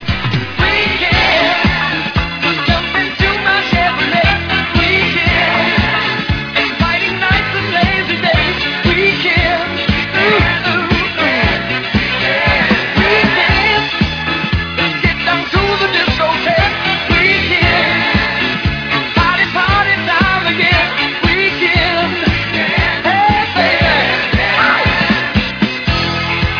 "Blue eyes Soul."